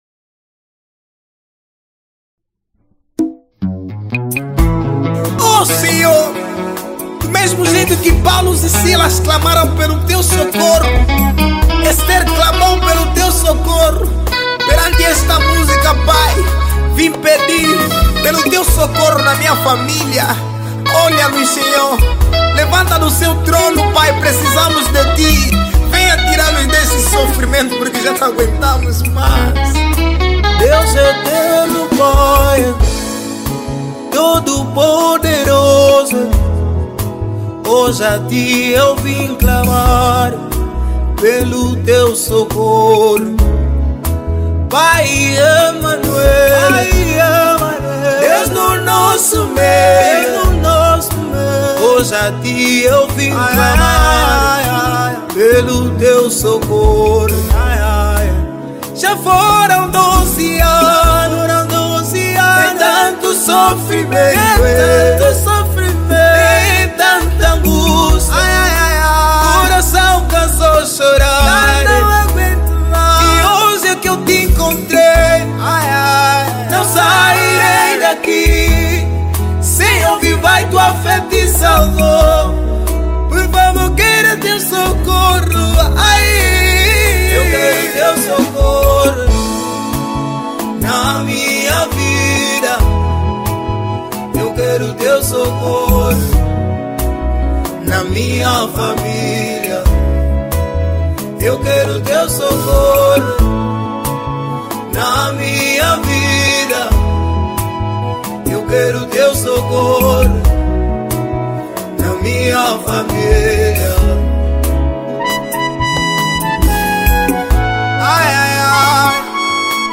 Gospel 2019